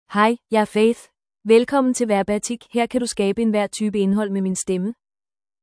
Faith — Female Danish AI voice
Faith is a female AI voice for Danish (Denmark).
Voice sample
Listen to Faith's female Danish voice.
Faith delivers clear pronunciation with authentic Denmark Danish intonation, making your content sound professionally produced.